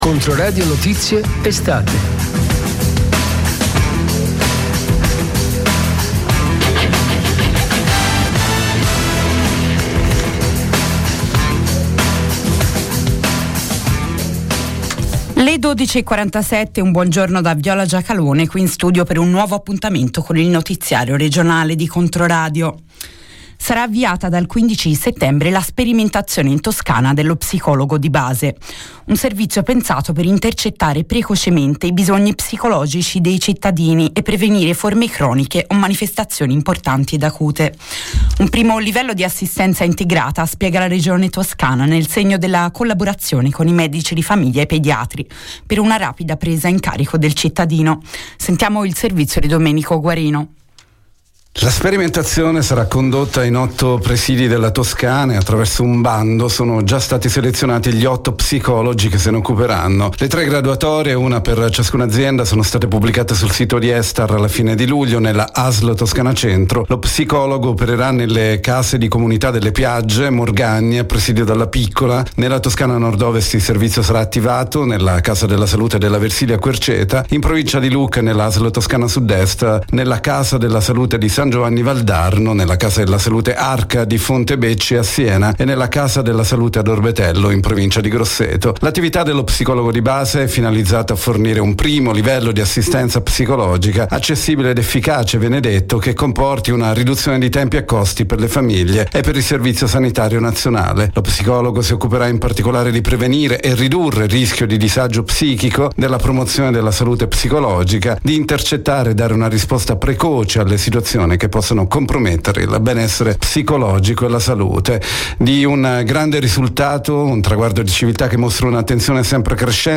Notiziario regionale - del 20/08/2024 ore 12:45